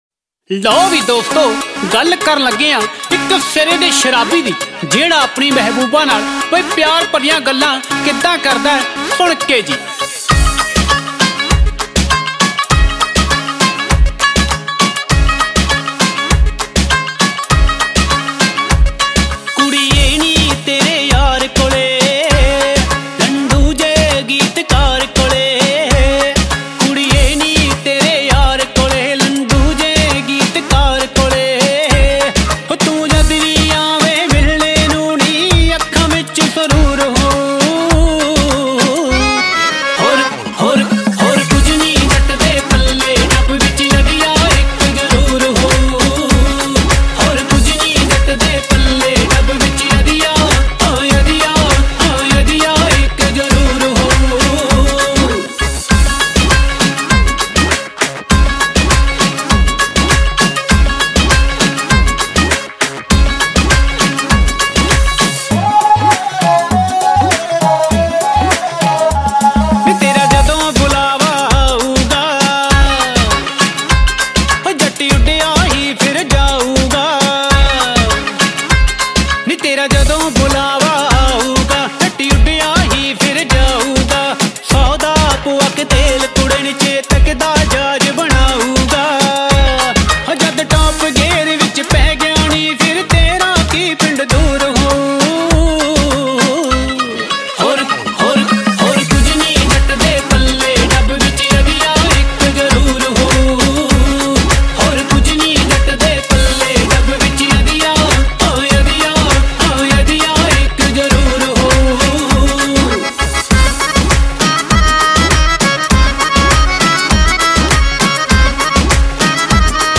Song Genre : Punjabi Old Song 2